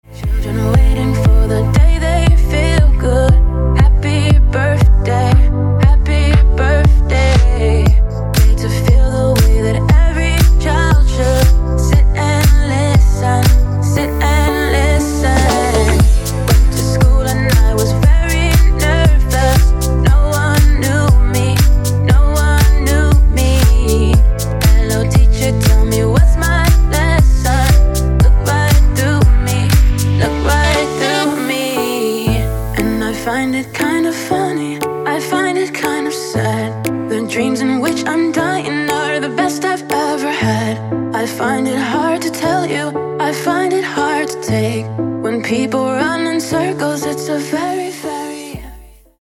• Качество: 160, Stereo
deep house
dance
club
romantic
vocal